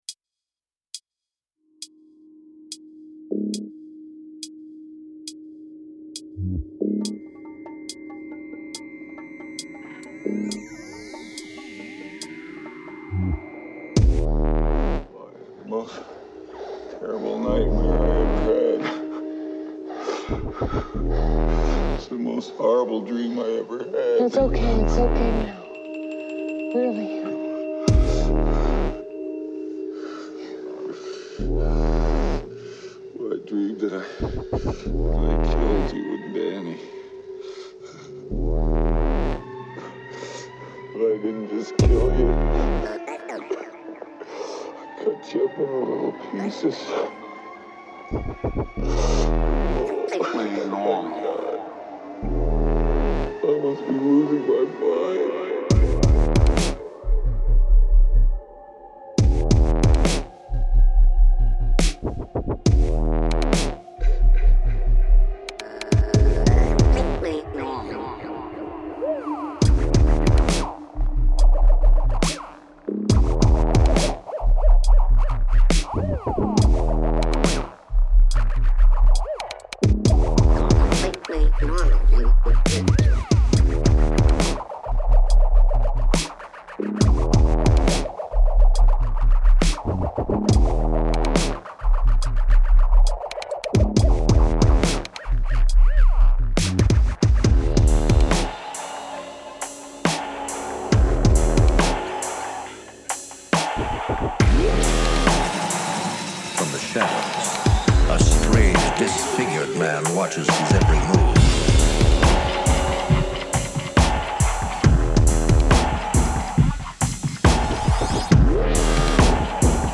Join me on this brutal journey of horror and dubstep.